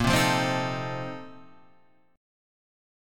A#M7sus2sus4 chord